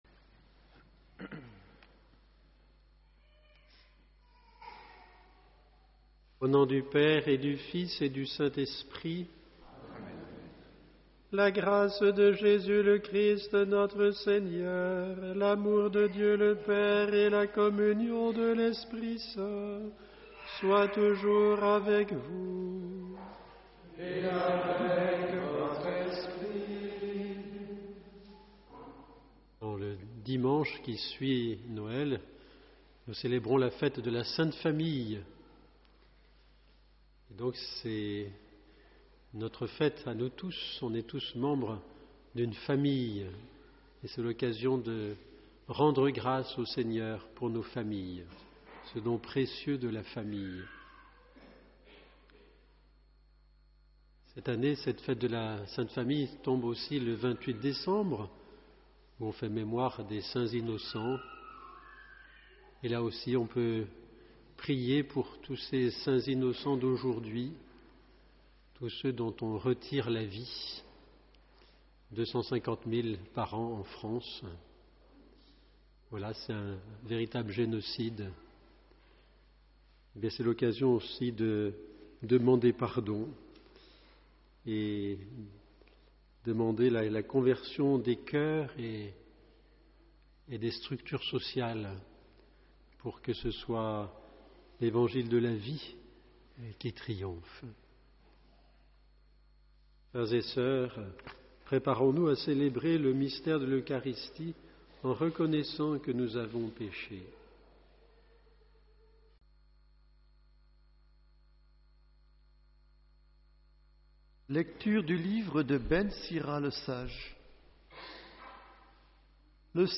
Vous pouvez retrouver l’intégralité de la Messe sur Youtube
HOMÉLIE La Fête de la Sainte Famille est riche d’enseignements pour nos familles, elle nous dit d’abord que, la famille n’est pas seulement une institution établie par la société.